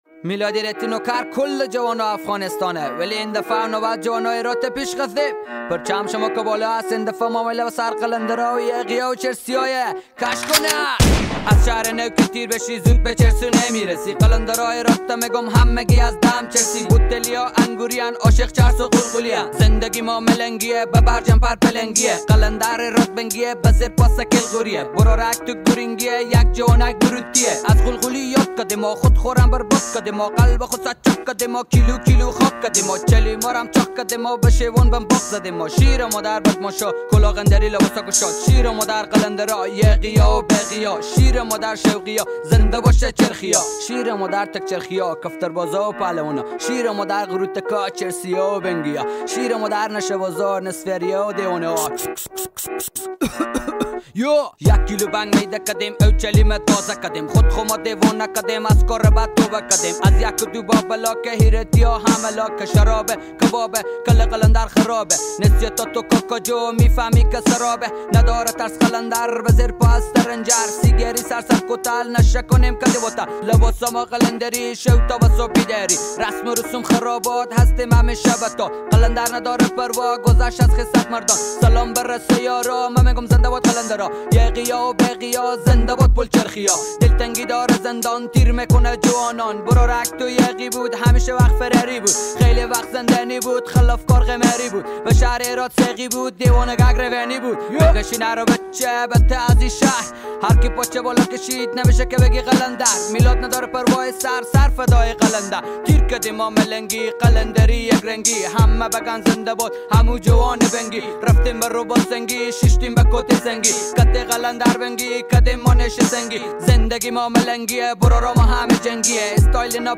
رپ افغانی